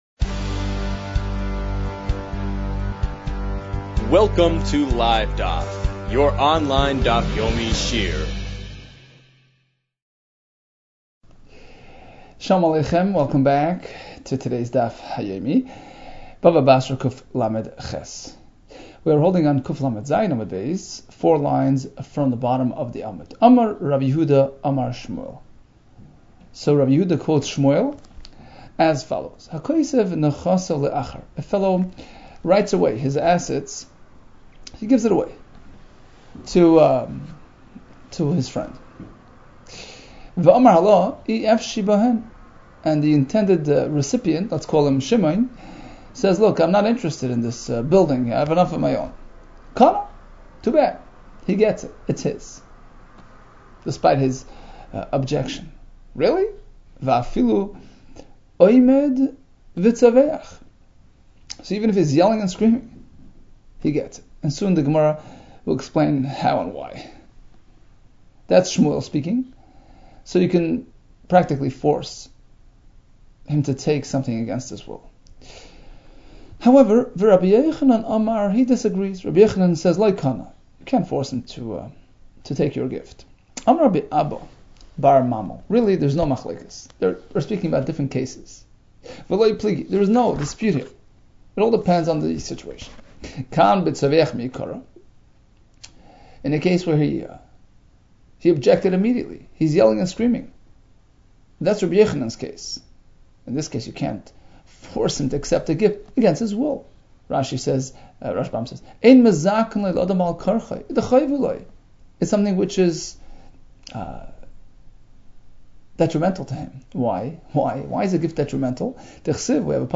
Bava Basra 138 - בבא בתרא קלח | Daf Yomi Online Shiur | Livedaf